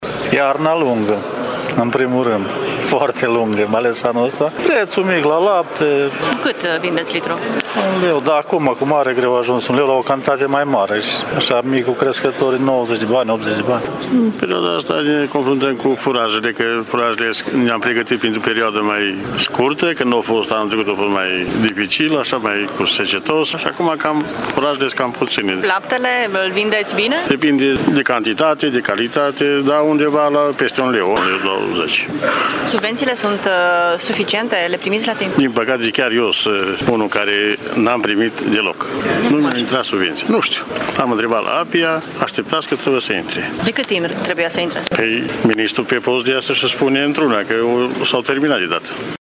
Sala Mare a Palatului Administrativ din Tîrgu-Mureș a găzduit, astăzi, ședința Adunării generale a Federației Crescătorilor de Bovine din România, la care au participat fermieri din țară dar și autorități și organizații profesionale.